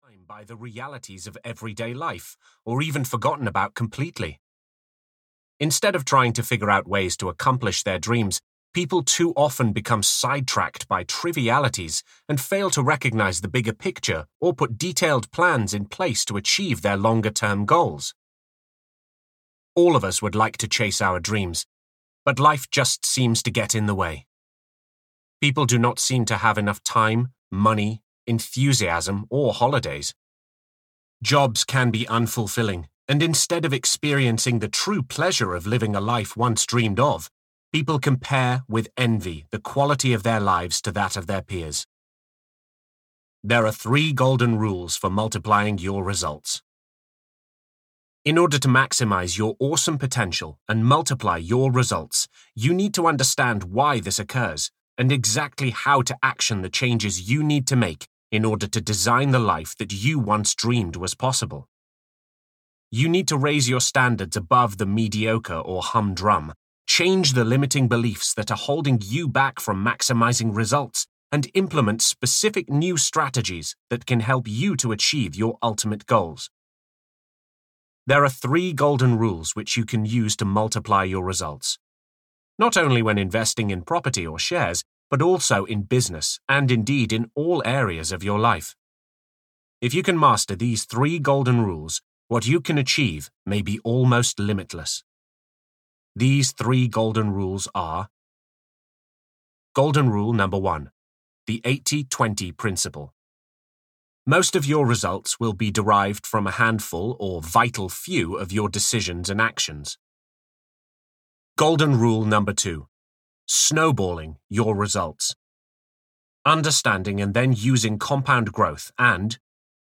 Take a Financial Leap: The 3 golden Rules for Financial Life Success (EN) audiokniha
Ukázka z knihy